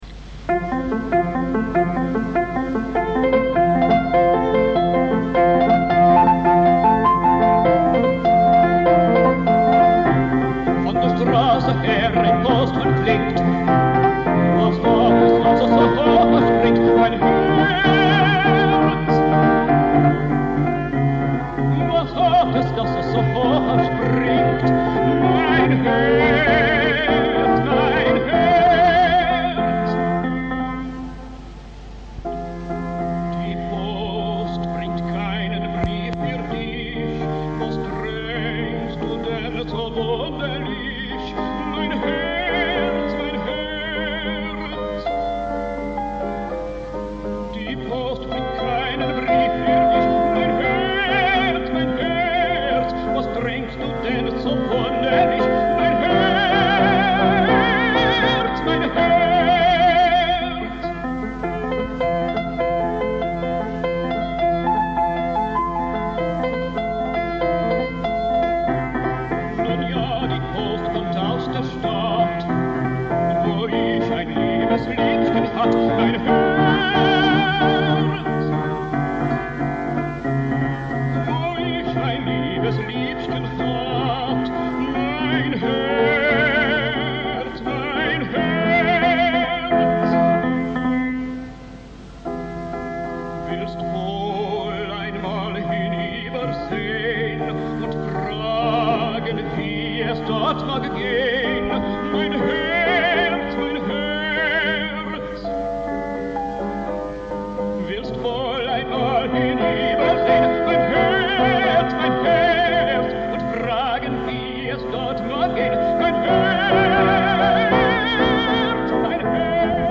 The live recordings